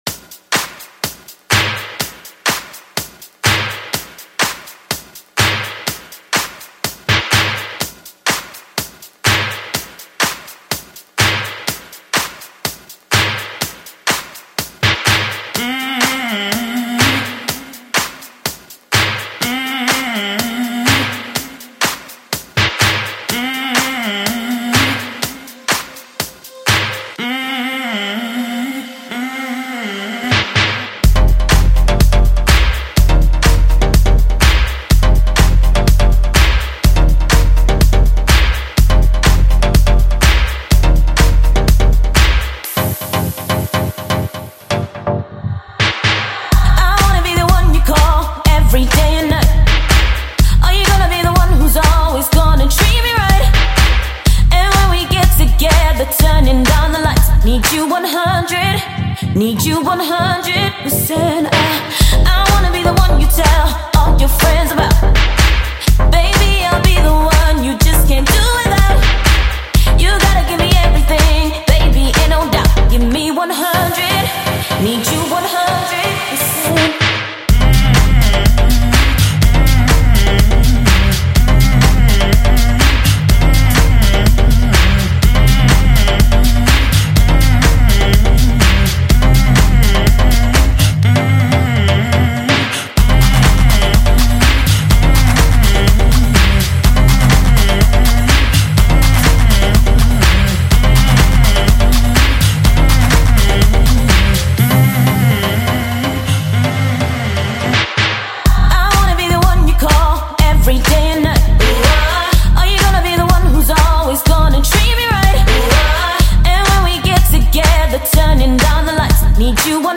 певицы
британский диджей и продюсер танцевальной музыки